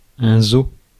Ääntäminen
France (Paris): IPA: [ɛ̃ zo]